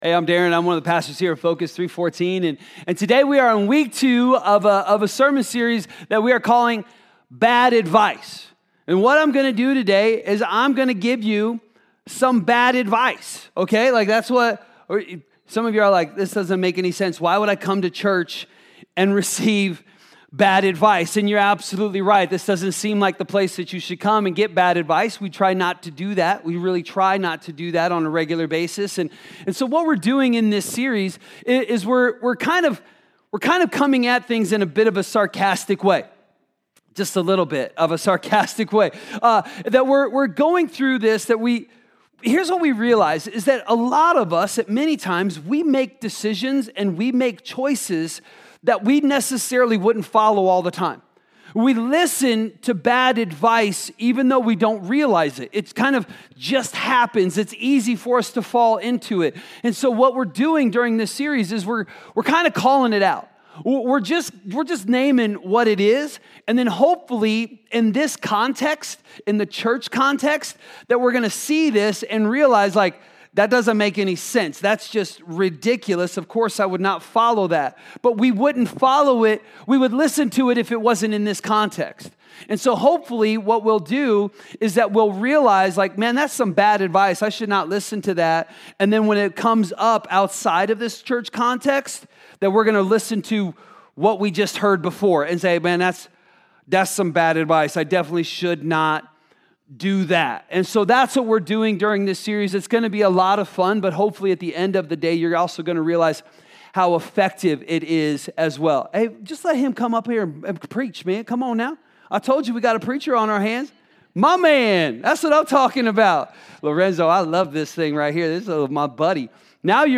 A message from the series "Bad Advice."